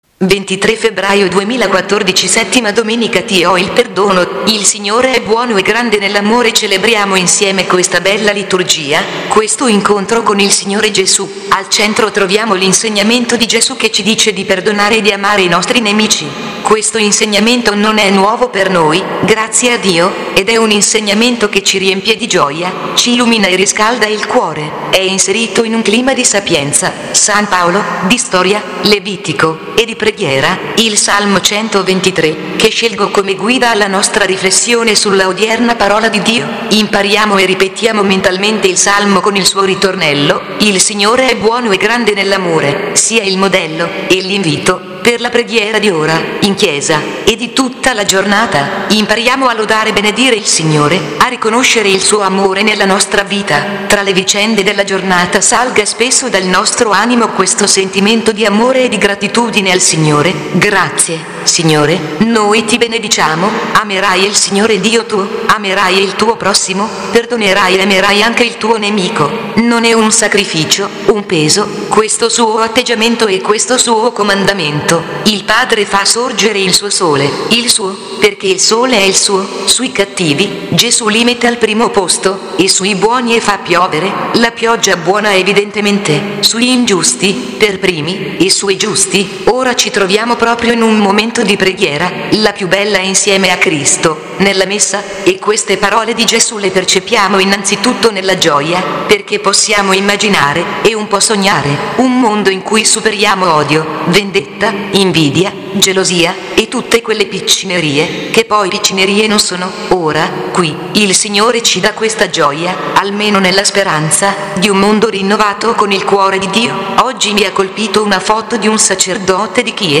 [clicca in fondo al testo per la lettura automatica]